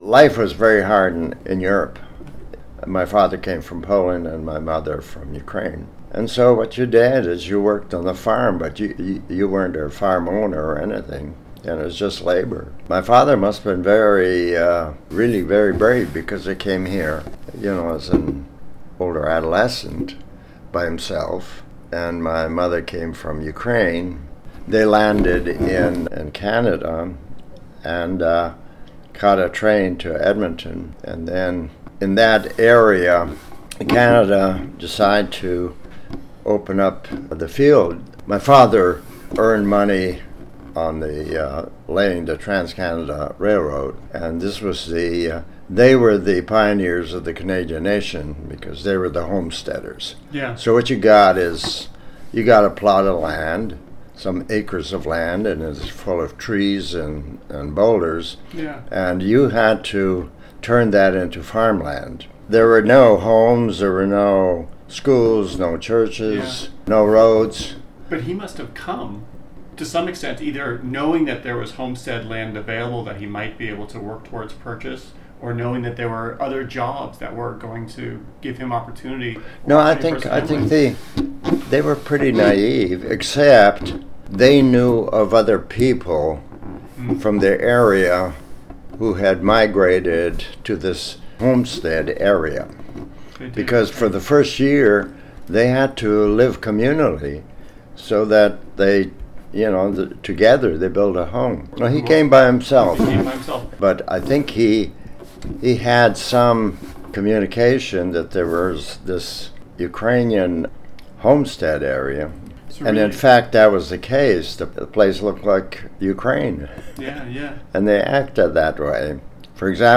And so, it is with great pleasure that I share with you some tidbits, excerpts, and reflections from my conversations with Dr. Albert Bandura, the David Jordan Professor Emeritus of Social Science in Psychology at Stanford University.